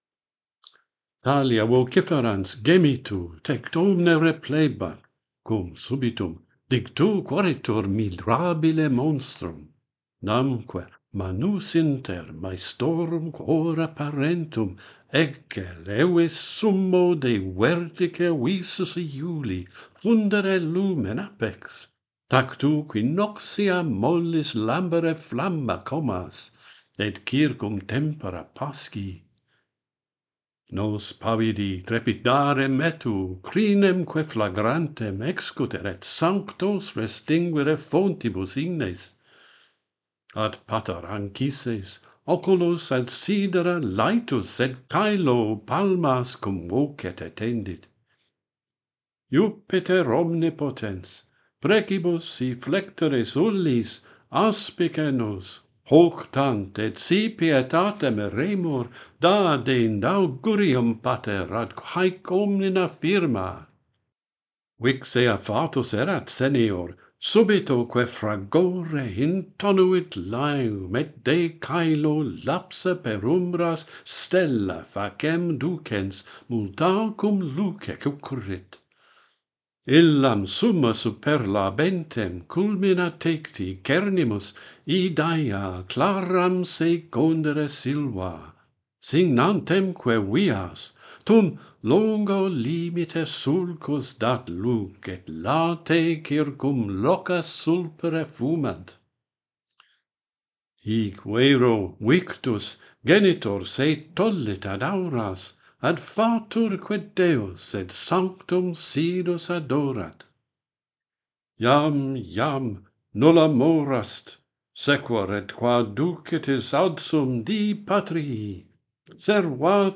Aeneas rescues his Father Anchises - Pantheon Poets | Latin Poetry Recited and Translated